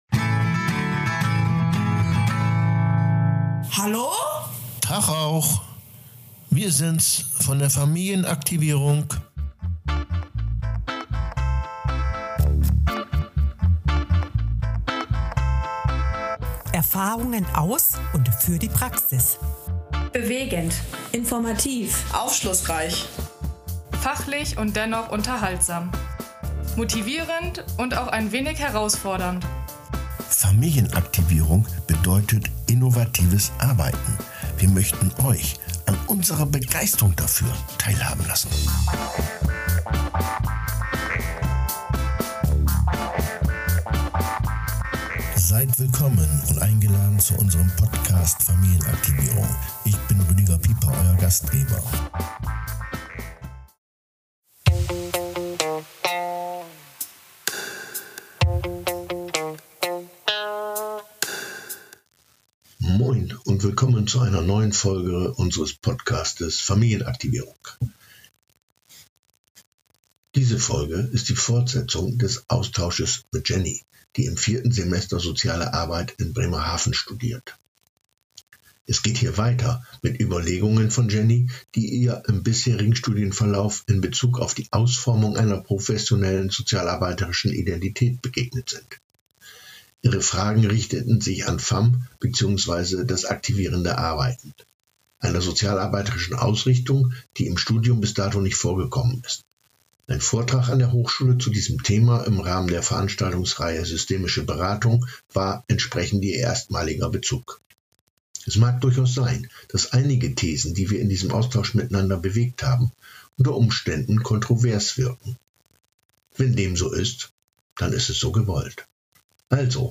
Die Fortsetzung des Gesprächs.